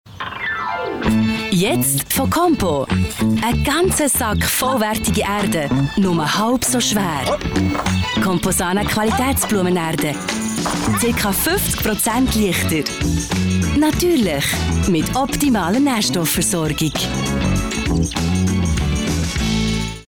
Werbung Schweizerdeutsch (BE)
Sprecherin mit breitem Einsatzspektrum.